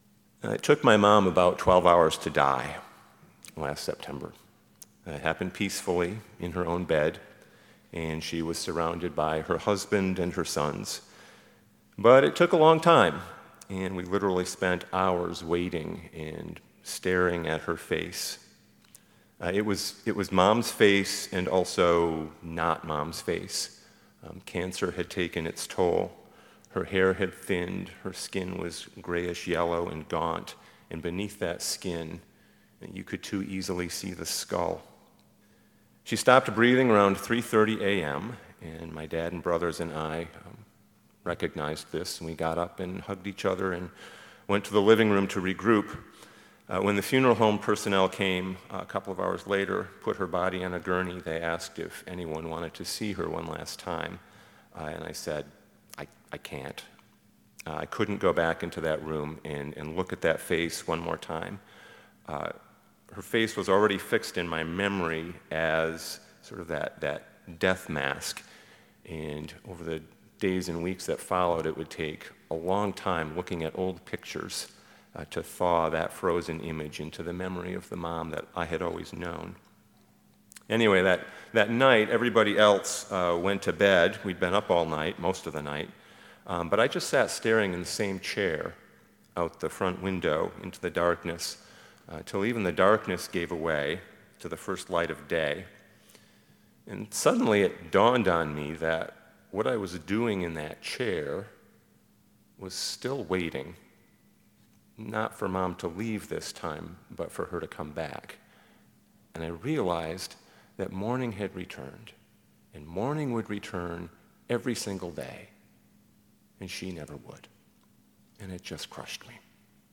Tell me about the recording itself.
Easter Testimony Service